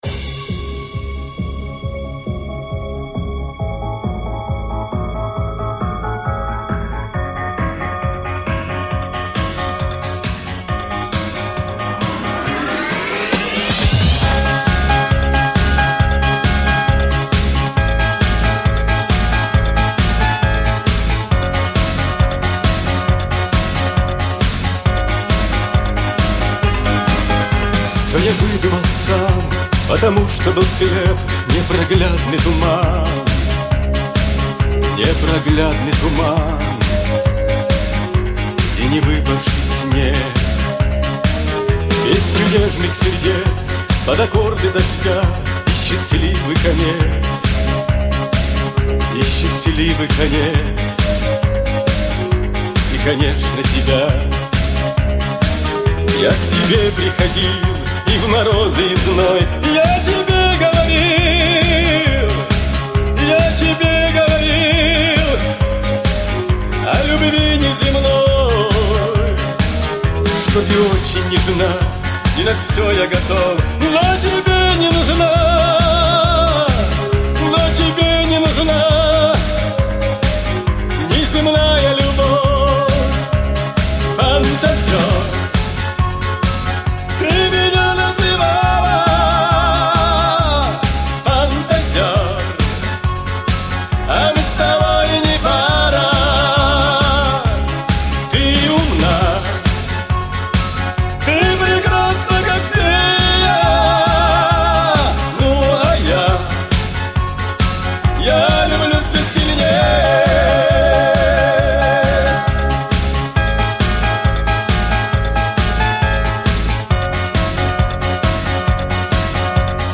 Ретро музыка
ретро песни